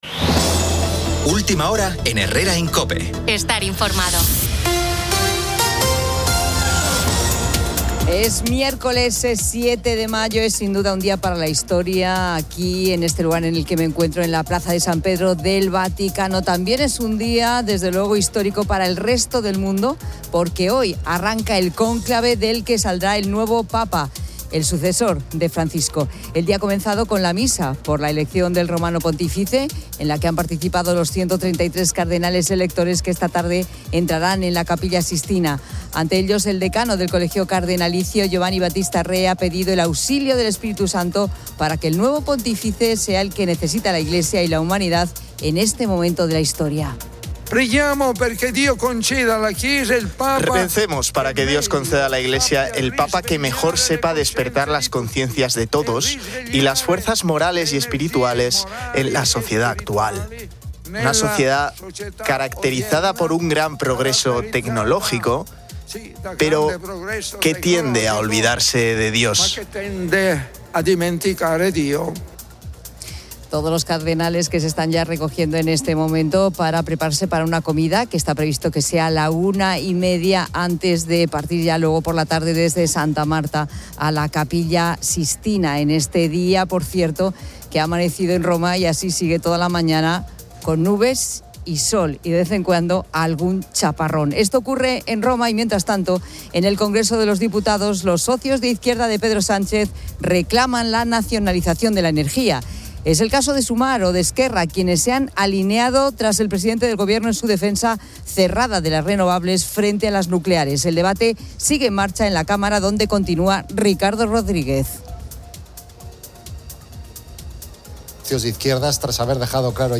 Es miércoles 7 de mayo, es sin duda un día para la historia aquí en este lugar en el que me encuentro, en la Plaza de San Pedro del Vaticano. También es un día, desde luego histórico para el resto del mundo porque hoy arranca el cónclave del que saldrá el nuevo Papa, el sucesor de Francisco.